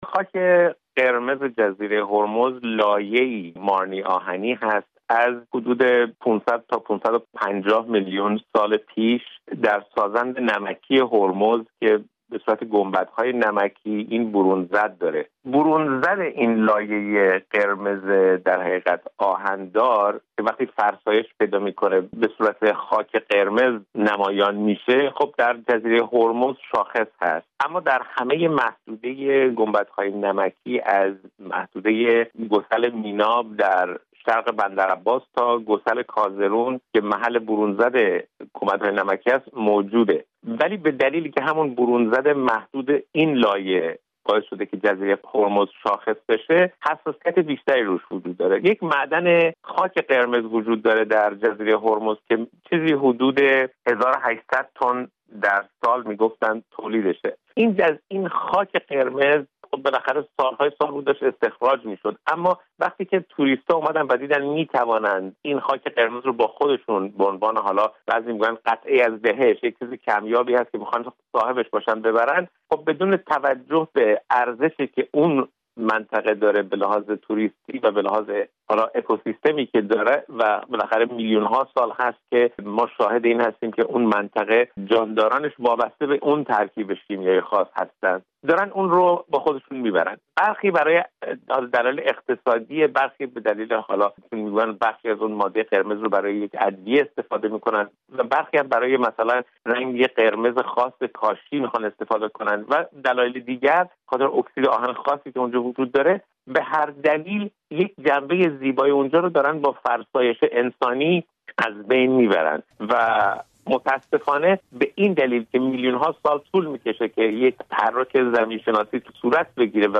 پدیده «قاچاق خاک» جزیرهٔ هرمز در گفت‌وگو